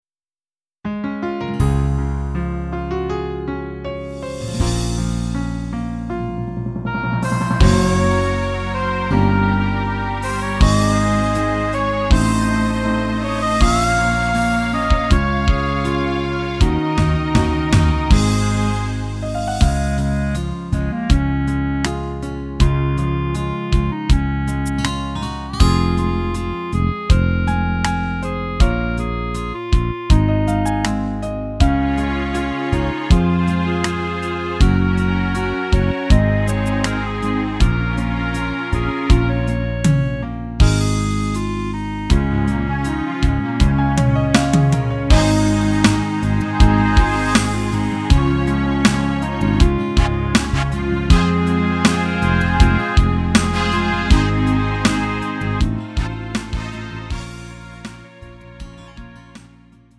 음정 원키 3:30
장르 가요 구분 Lite MR
Lite MR은 저렴한 가격에 간단한 연습이나 취미용으로 활용할 수 있는 가벼운 반주입니다.